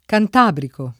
cantabrico [ kant # briko ]